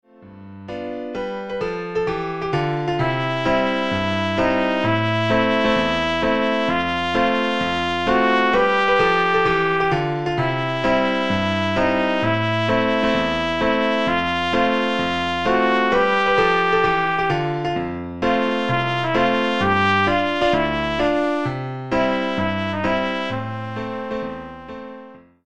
Sample from the Rehearsal MP3